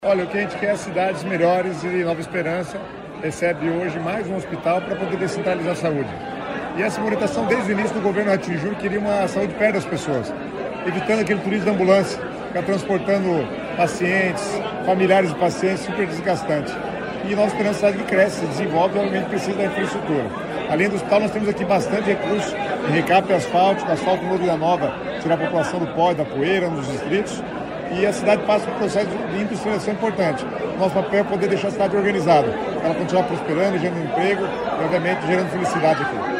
Sonora do secretário Estadual das Cidades, Guto Silva, sobre o anúncio do novo hospital de Nova Esperança